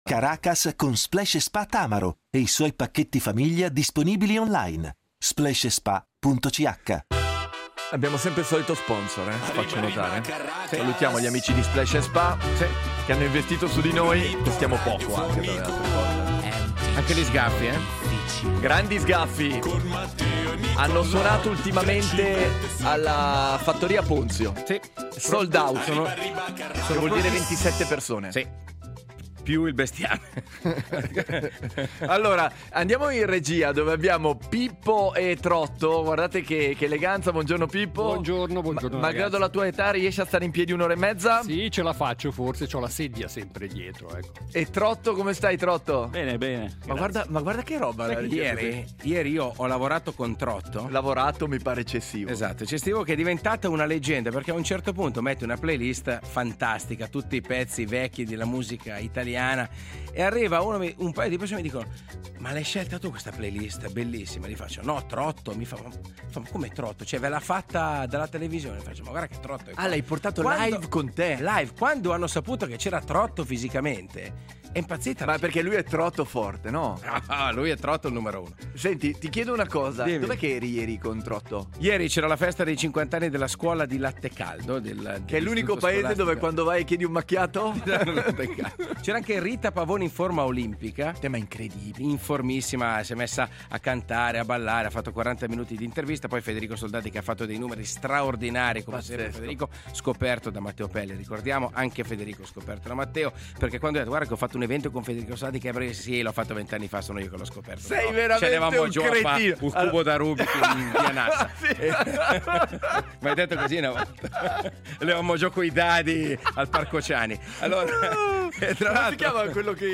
Ovviamente con una serie di regole radiofoniche infrante, ovviamente con invettive contro tutto e tutti.